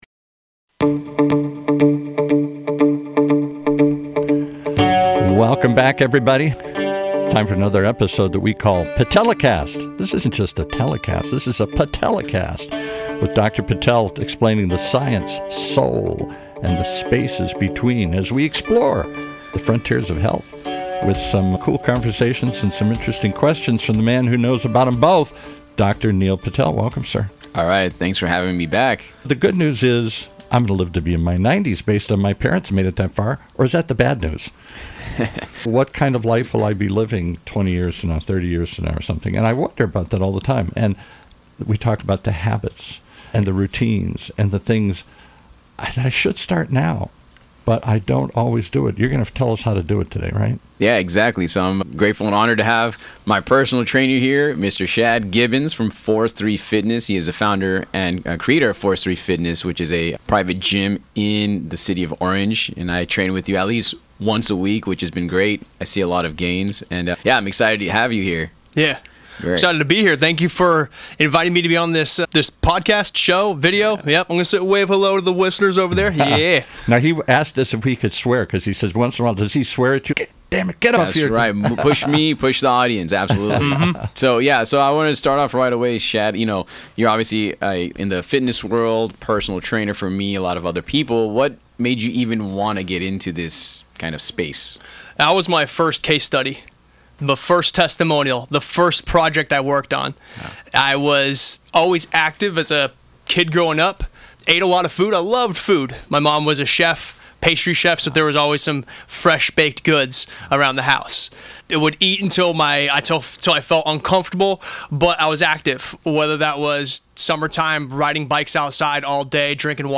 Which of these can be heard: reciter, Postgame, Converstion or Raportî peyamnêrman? Converstion